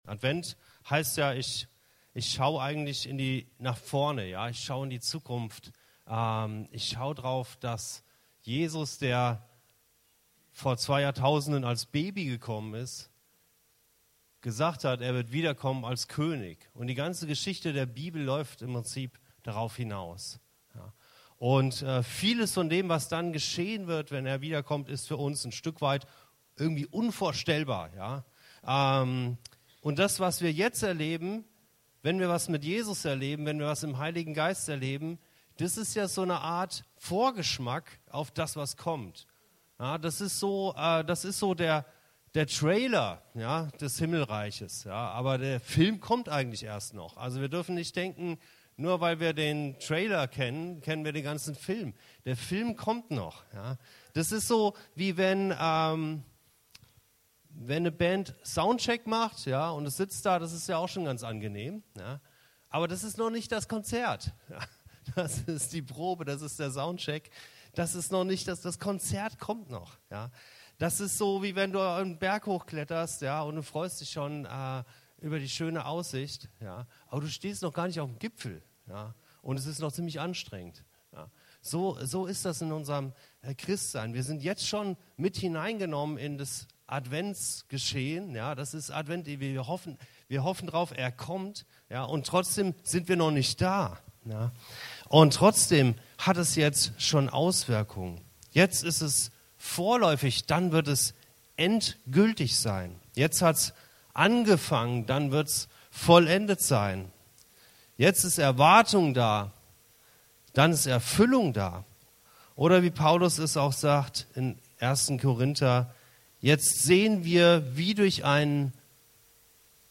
Advent als Lebensstil - das verändert alles ~ Anskar-Kirche Hamburg- Predigten Podcast